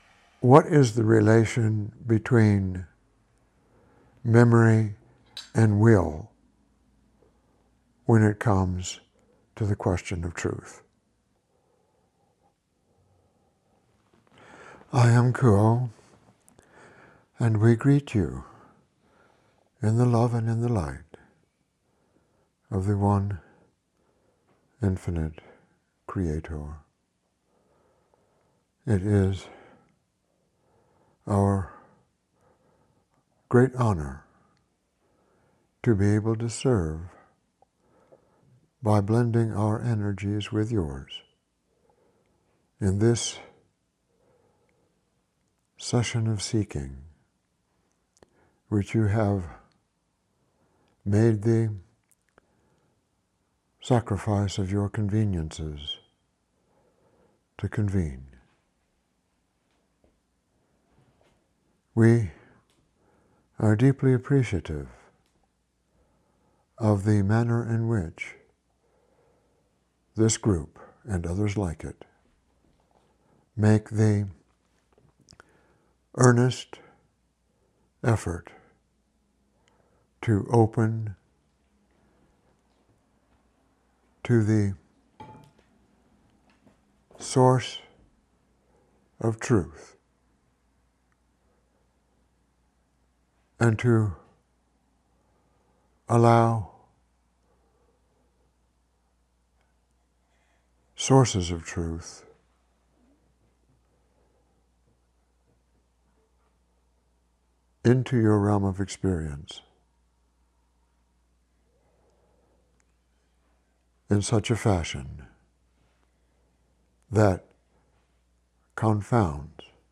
Channeled message